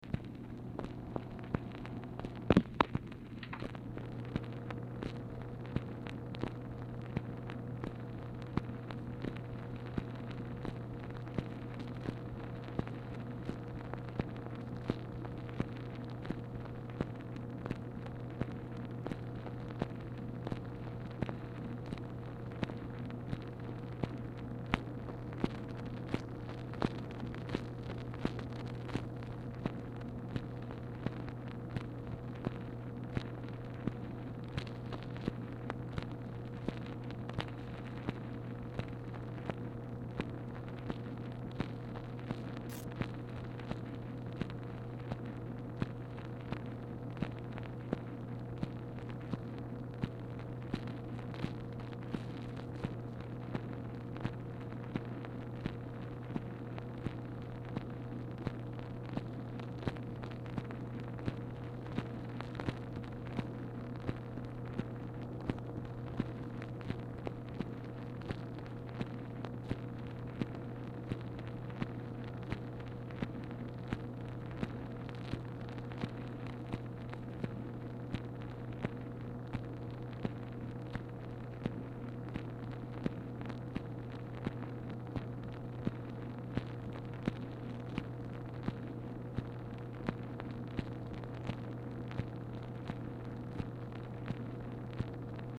Telephone conversation # 7695, sound recording, MACHINE NOISE, 5/14/1965, time unknown | Discover LBJ
Format Dictation belt
Location Of Speaker 1 Mansion, White House, Washington, DC
Speaker 2 MACHINE NOISE